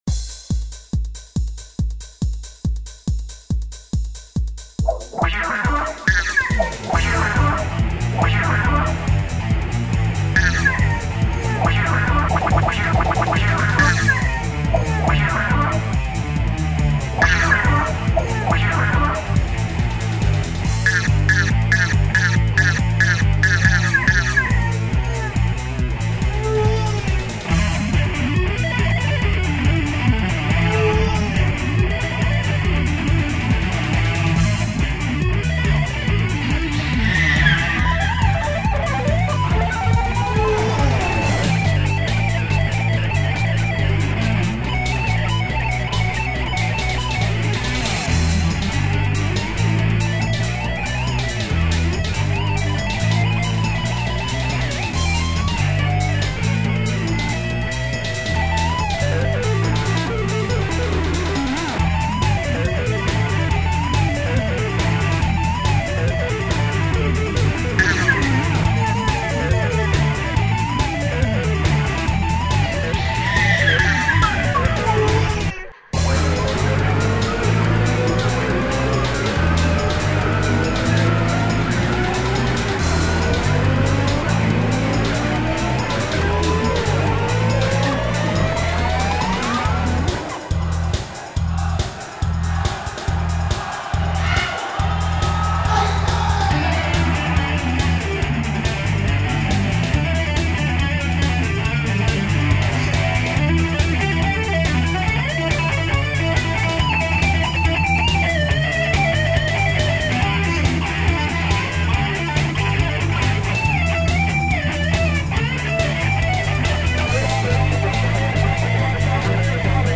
АЛЬТЕРНАТИВНАЯ МУЗЫКА